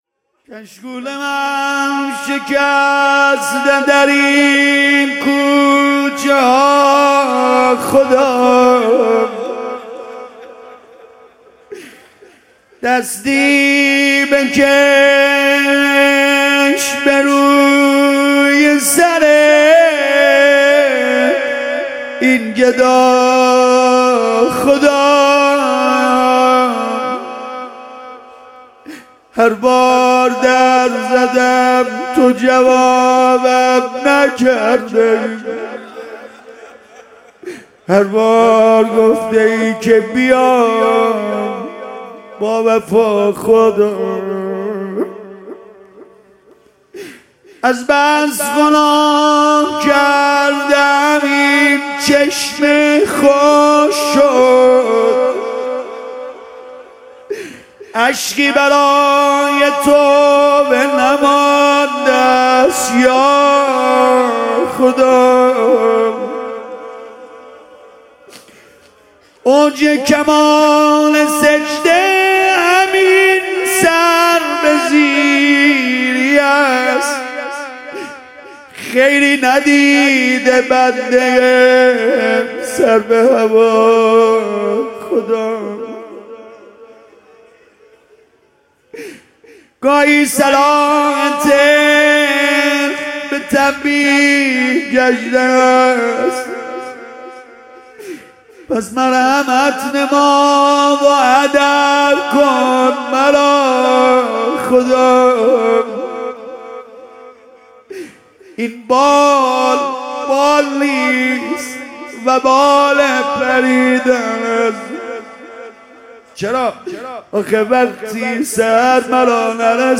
مناجات خداصوتی کشکول من شکسته در این کوچه ها خدا | مناجات - پادکست پارسی